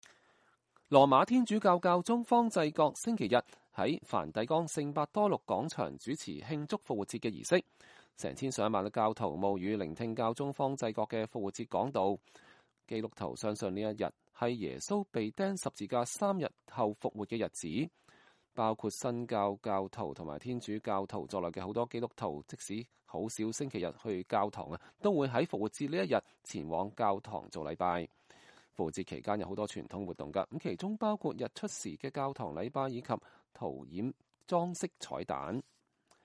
教宗方濟各在梵蒂岡聖伯多祿廣場主持慶祝復活節儀式
羅馬天主教教宗方濟各星期日在梵蒂岡聖伯多祿廣場主持慶祝復活節的儀式。成千上萬的教徒冒雨聆聽教宗方濟各的復活節講道。